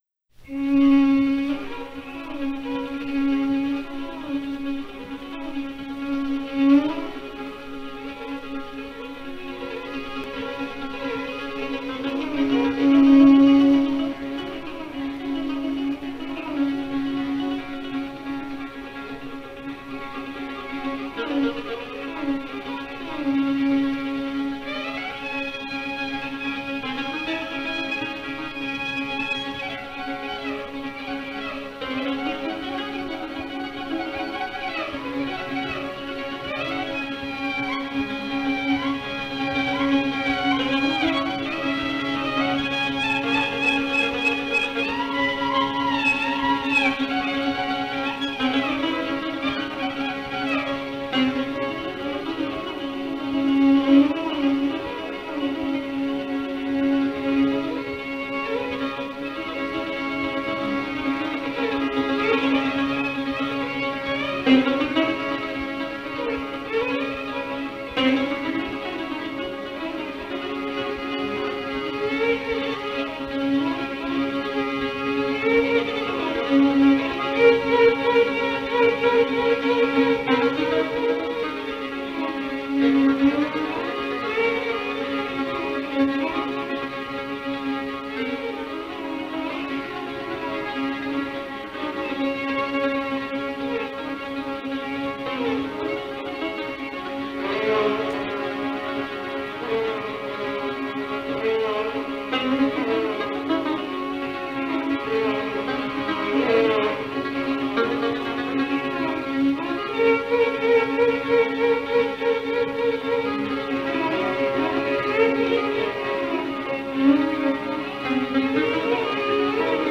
Kaba me violine
Kaba e tipit vajtimor me dy pjesё.
Pjesa e dytё ёshtё valle e gёzuar, qё shёnon kalimin nga errёsira nё dritё.